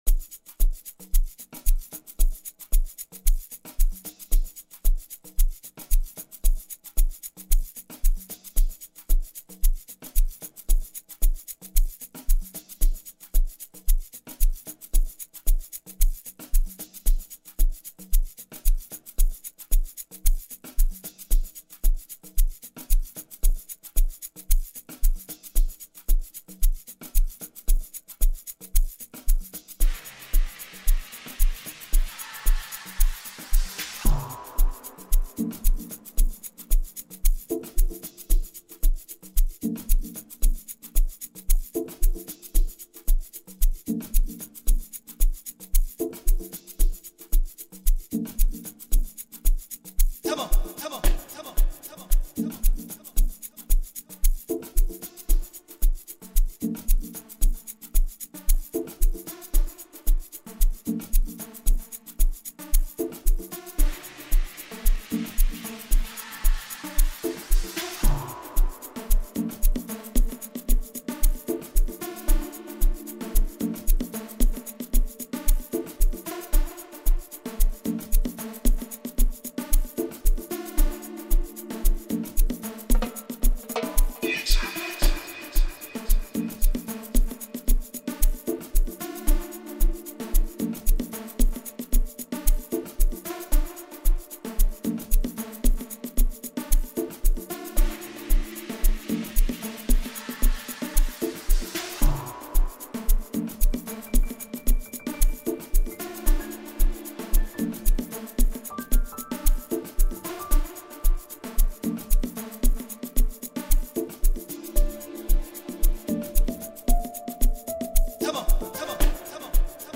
AMAPIANO song